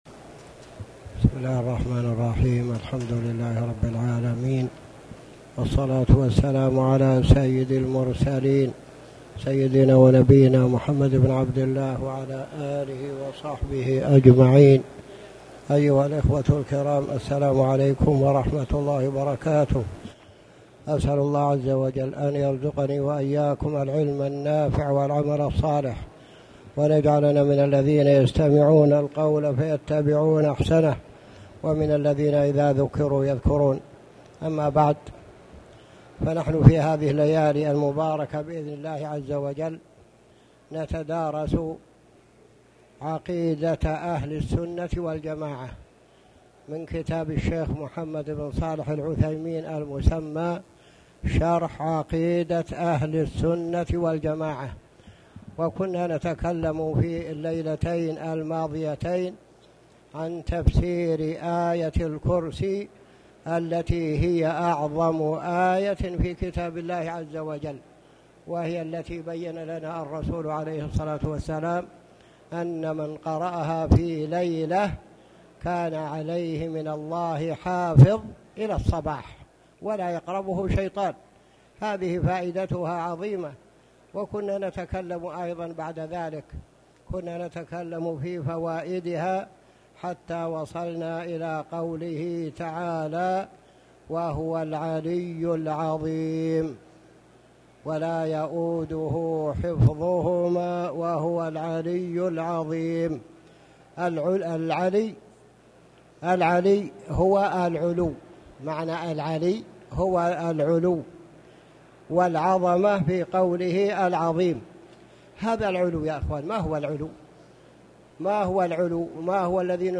تاريخ النشر ١٤ شعبان ١٤٣٨ هـ المكان: المسجد الحرام الشيخ